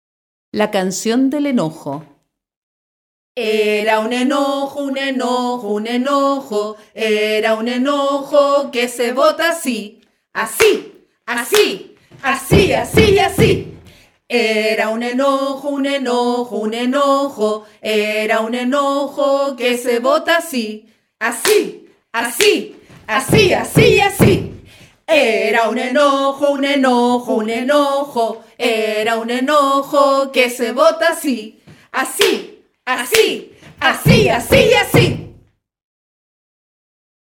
Audio con ejercicio de relajación para niños.